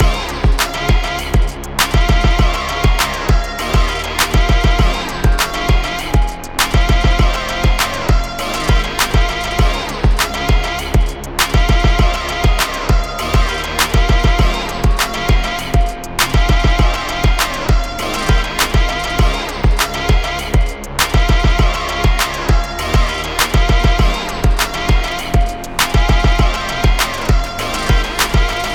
Chill
Music - Song Key
F Minor
Music - Shred Guitar
Live Hop
Music - Sitar